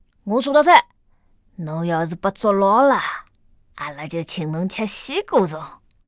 c01_5残疾小孩_3.ogg